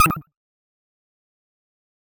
SpaceMoan.wav